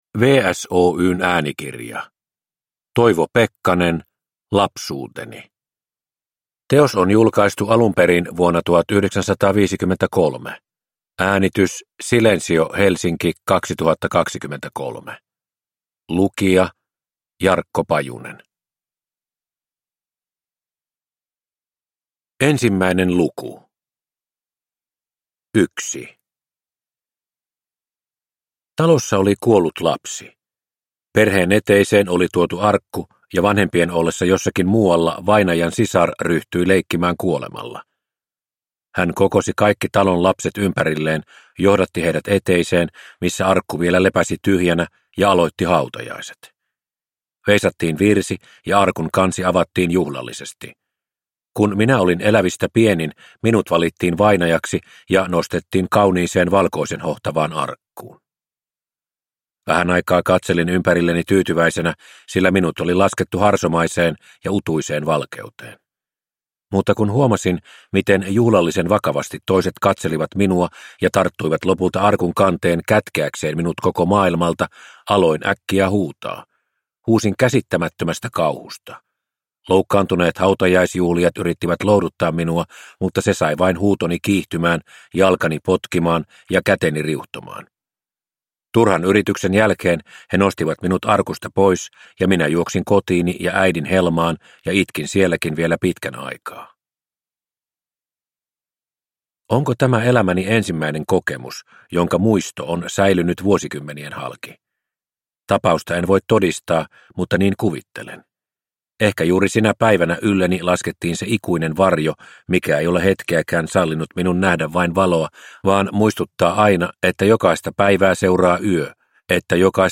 Lapsuuteni – Ljudbok – Laddas ner